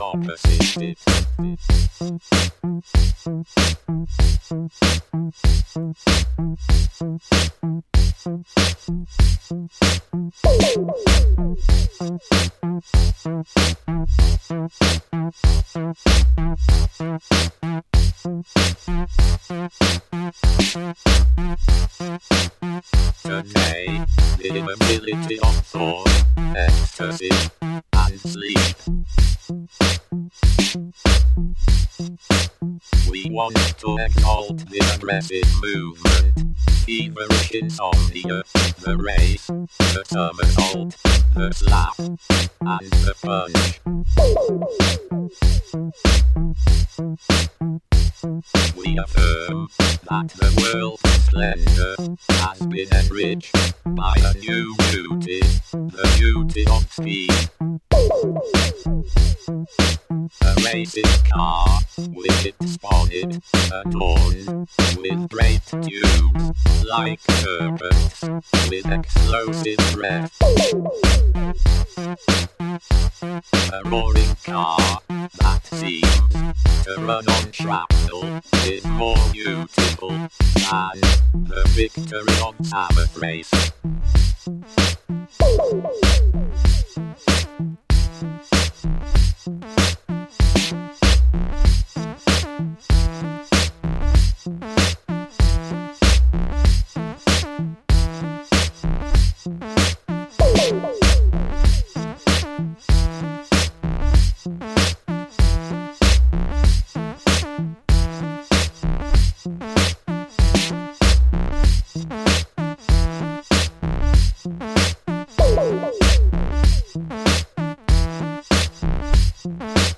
modern minimal techno
dancefloor weapons